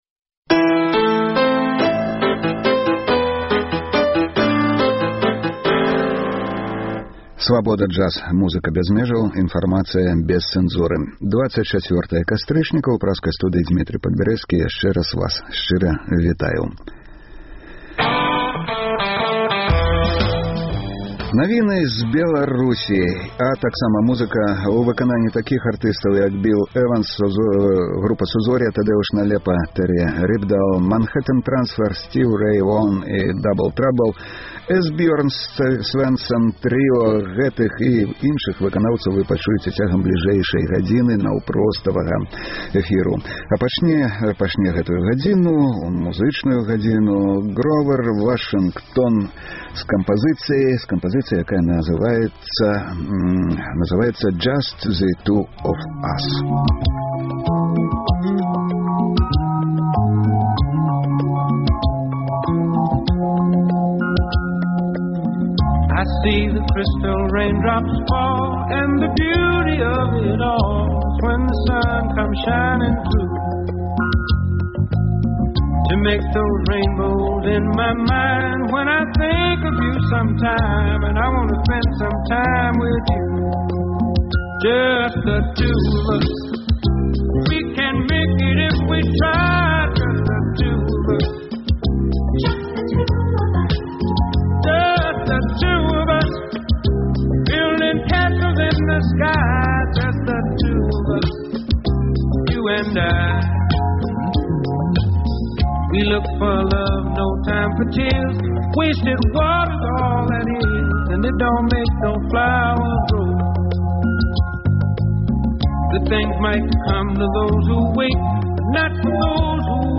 Слухайце ад 12:00 да 14:00 жывы эфір Свабоды!